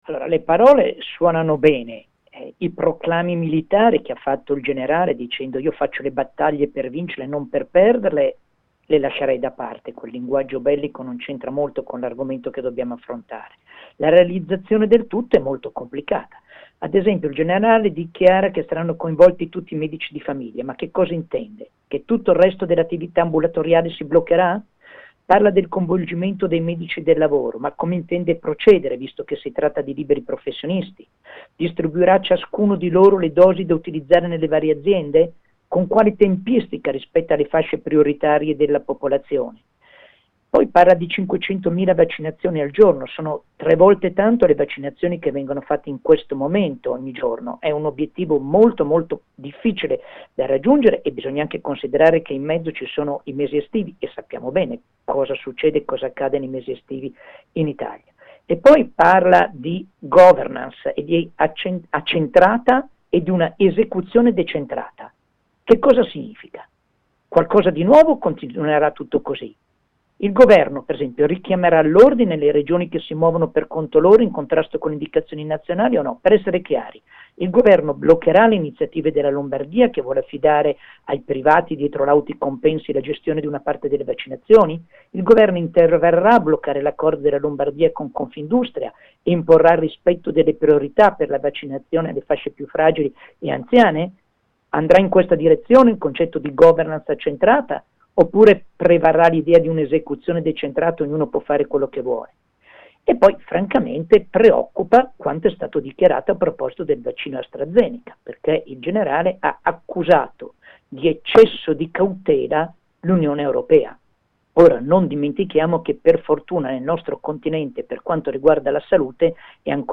Per un primo commento Vittorio Agnoletto, medico e nostro collaboratore.